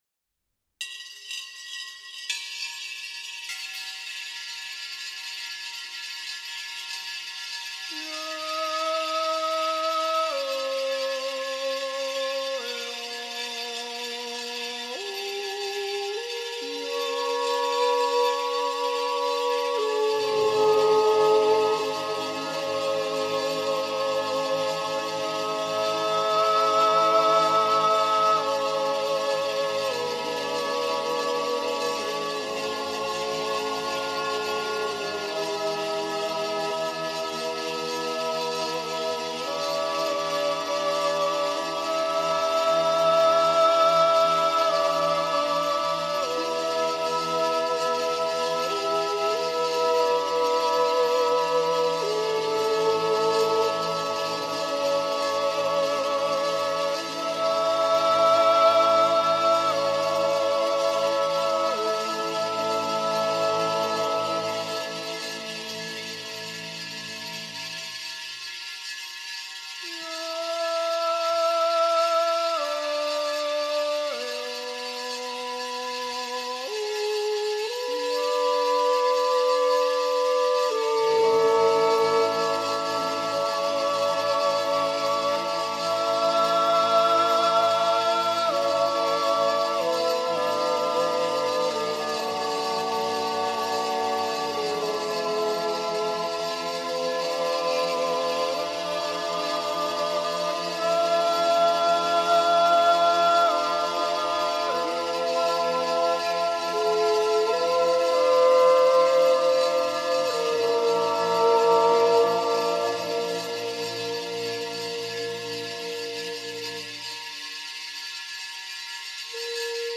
Jodelchörli Schönengrund: Zäuerli mit Talerschwingen – Yodel with coin rolling